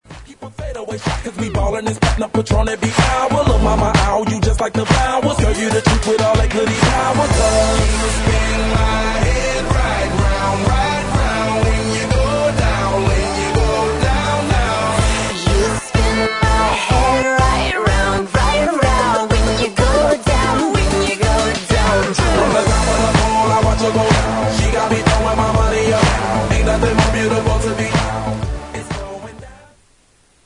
• Hip-Hop Ringtones